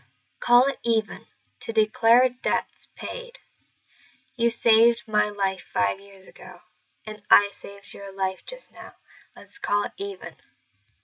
このイディオムは、直訳すると、「五分五分とする」という意味になり、特に金銭の貸し借り、状況、能力、競技について、「l貸し借りなしとする」を表します。 英語ネイティブによる発音は下記のリンクをクリックしてください。